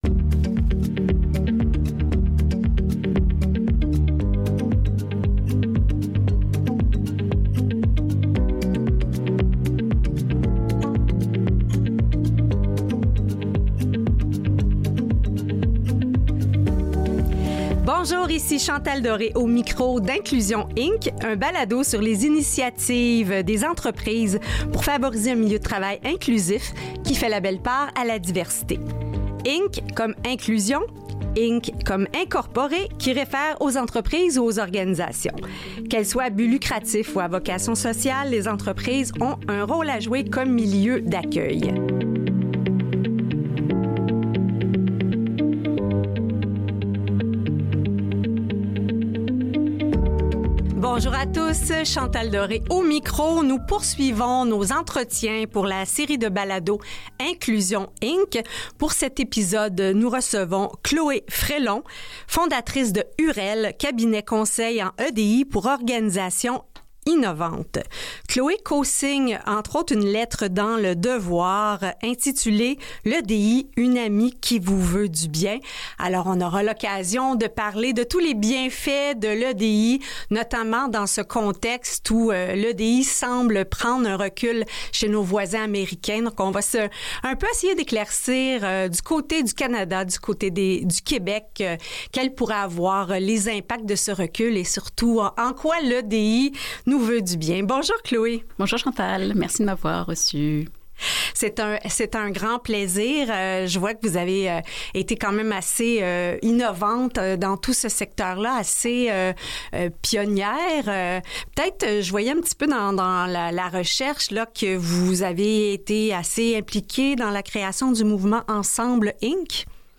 Un entretien fort intéressant